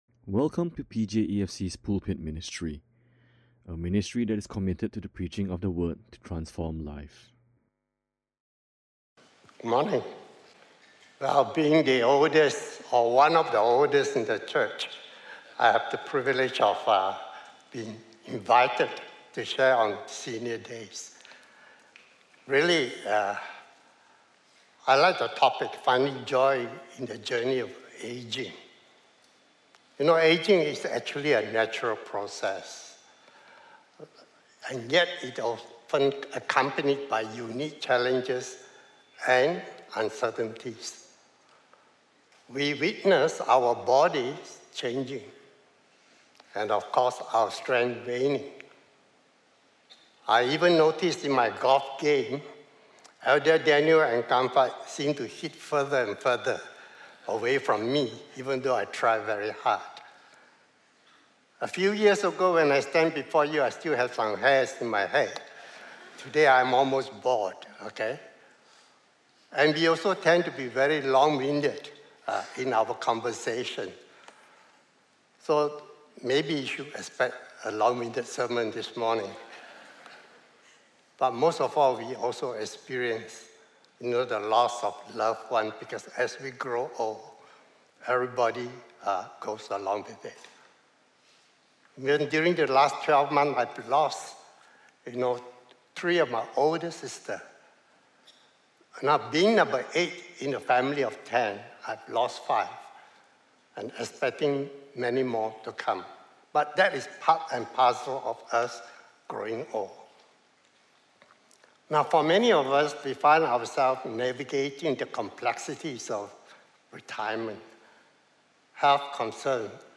This is a stand-alone sermon in conjunction with World Senior Citizen’s Day.